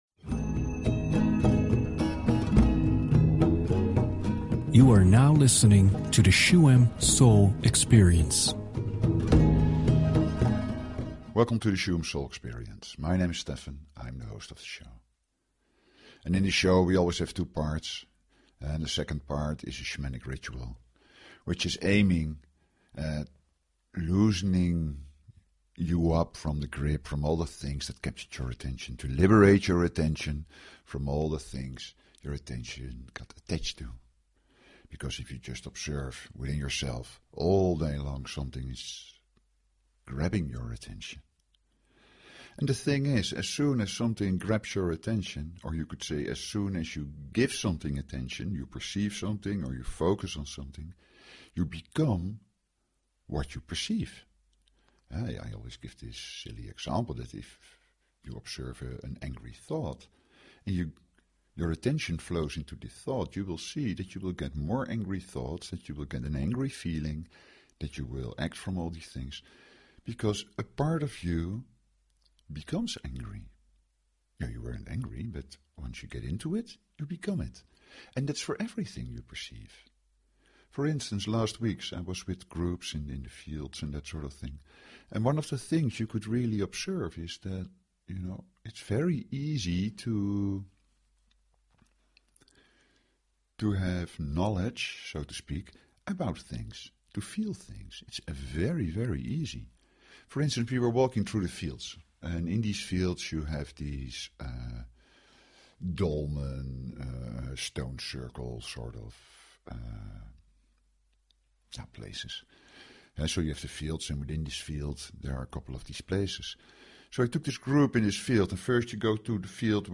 Talk Show Episode, Audio Podcast, Shuem_Soul_Experience and Courtesy of BBS Radio on , show guests , about , categorized as
To help you find positive fusion, enjoy the Reset Meditation in the second part of the show.